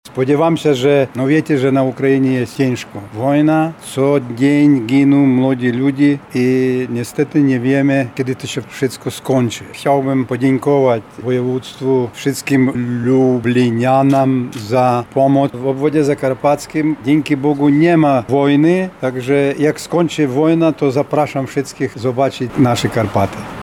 -mówi Iwan Hrycak, były konsul Ukrainy w Lublinie.